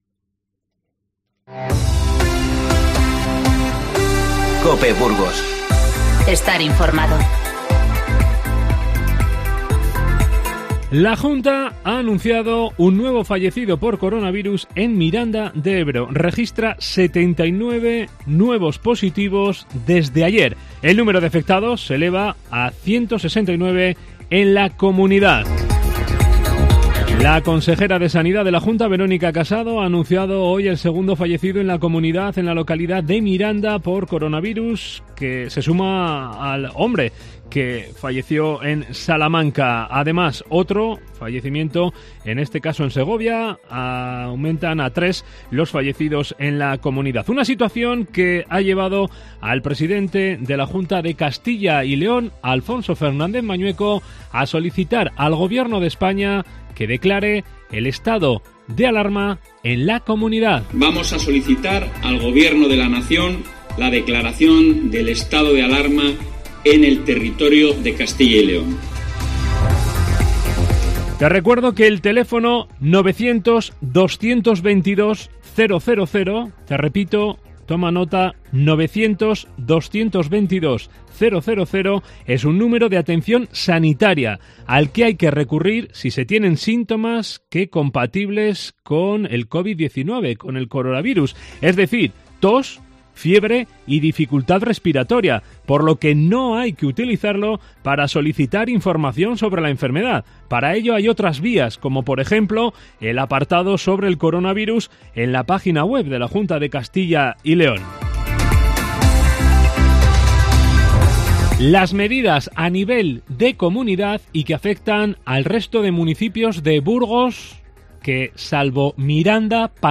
Informativo 13-03-20